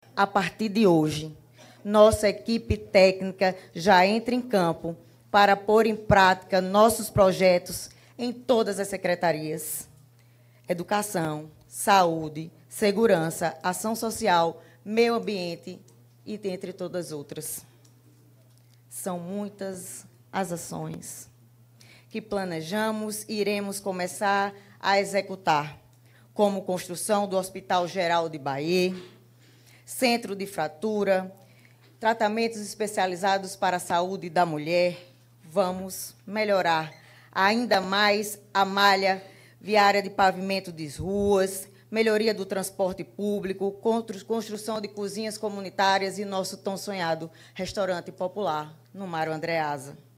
Os comentários de Tacyana Leitão foram registrados pelo programa Correio Debate, da 98 FM, de João Pessoa, nesta quinta-feira (02/01).